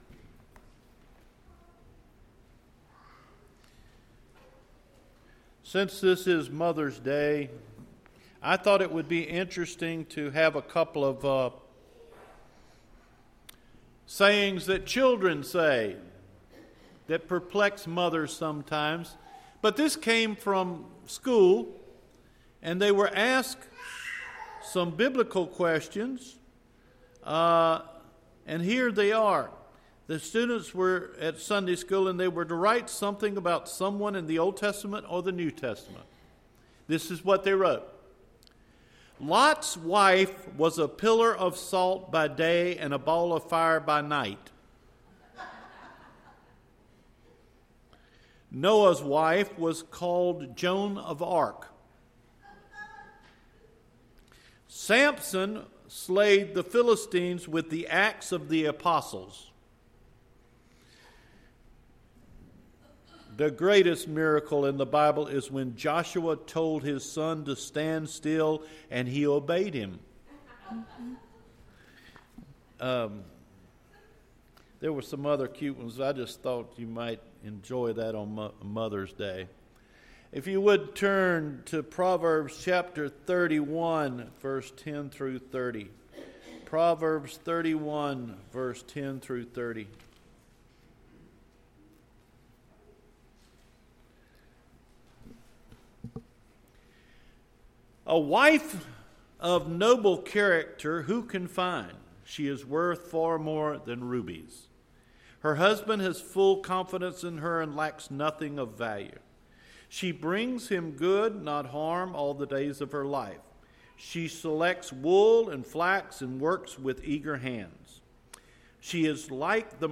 Mother’s Day – May 13 Sermon
Recorded Sermons